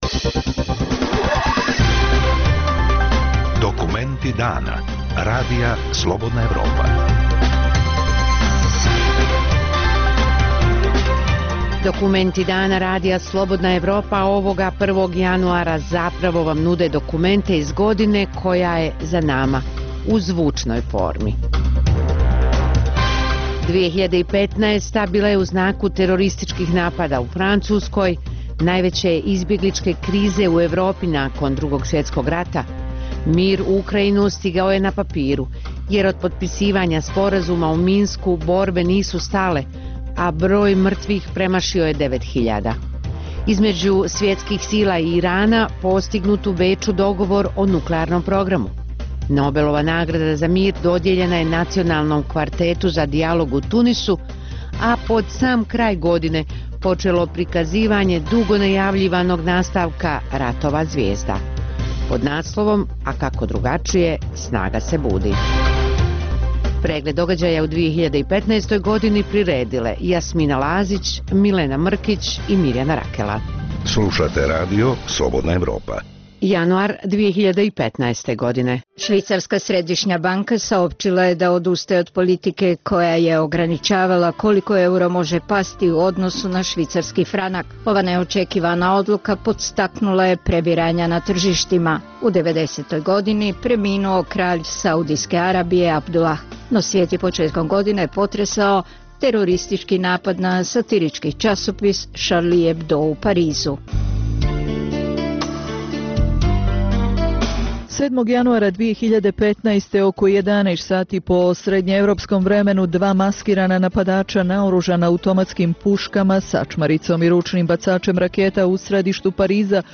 Dnevna emisija u kojoj dublje istražujemo aktuelne događaje koji nisu u prvom planu kroz intervjue, analize, komentare i reportaže.